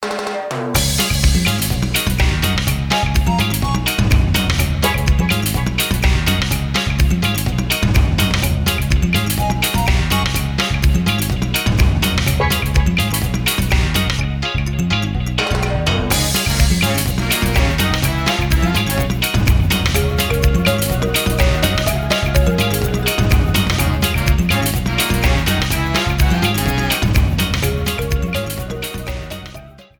Trimmed and added fadeout
Fair use music sample